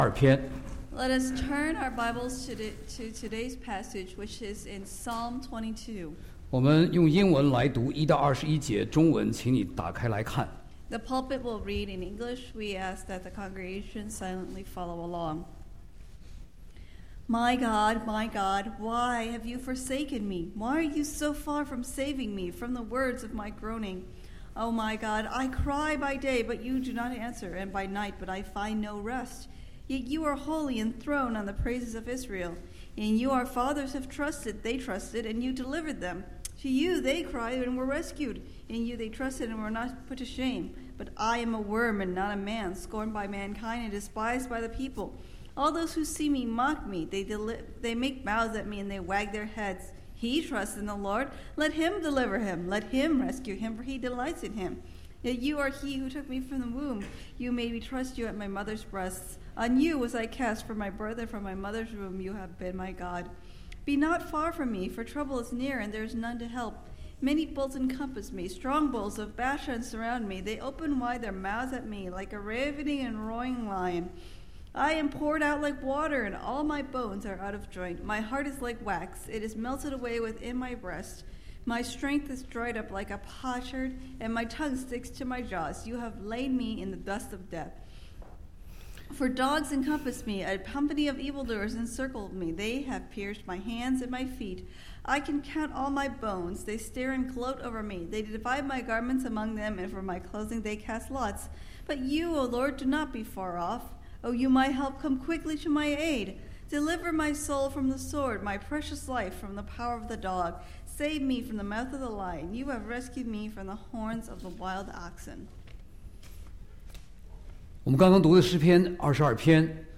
受难节信息：我的上帝为什么离弃我？